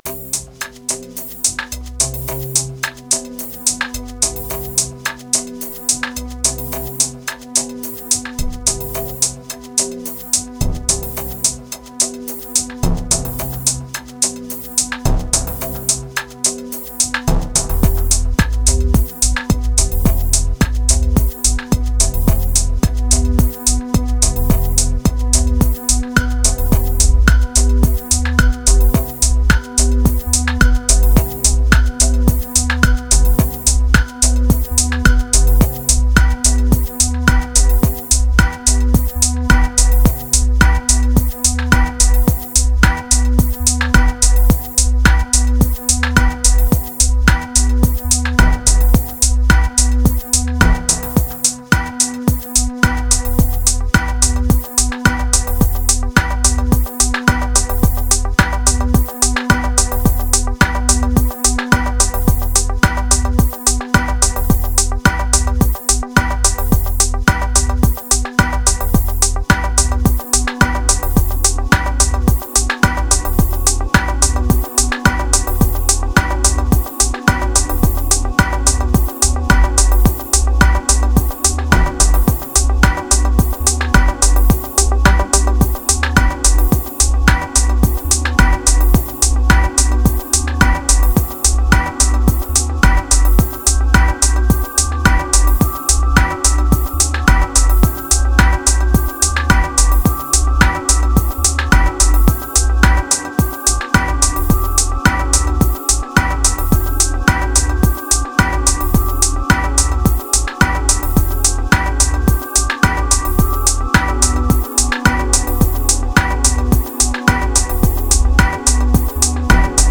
742📈 - 44%🤔 - 108BPM🔊 - 2020-11-11📅 - 327🌟
Kicks Techno Dub Lean Spring Ladder Riddim